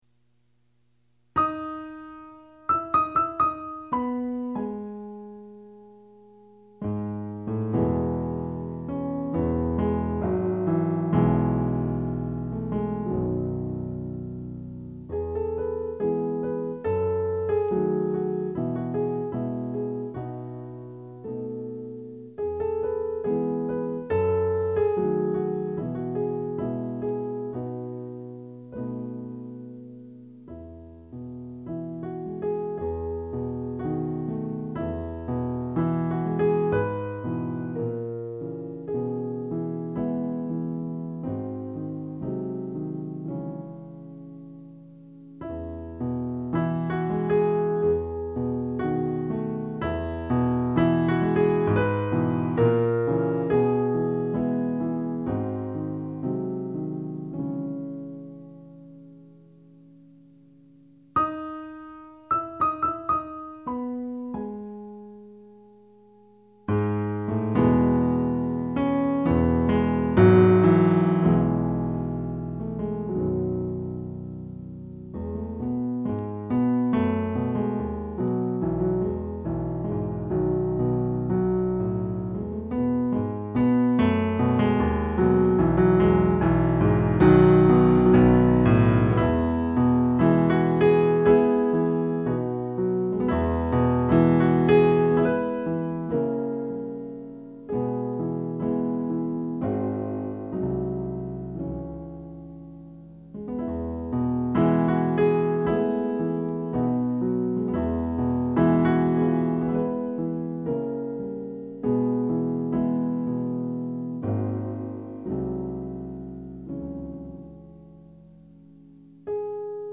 E major